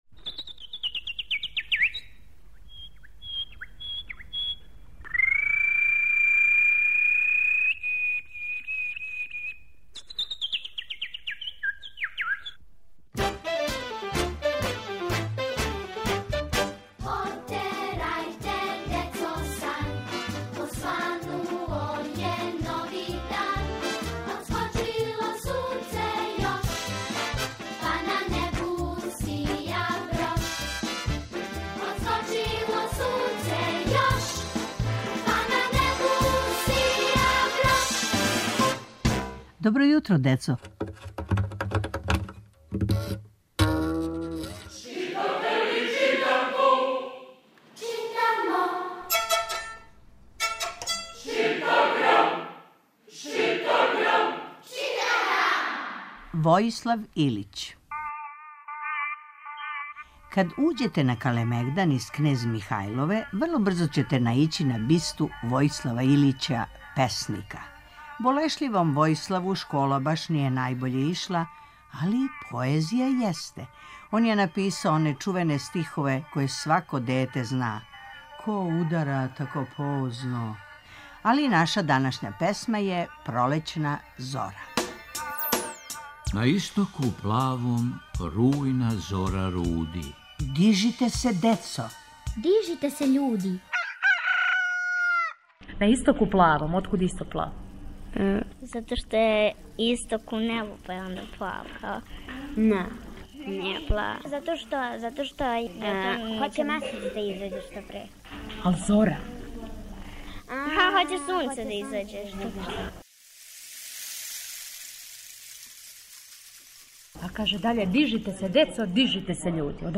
Сваког понедељка у емисији Добро јутро, децо - ЧИТАГРАМ: Читанка за слушање. Ове недеље - први разред, Војислав Илић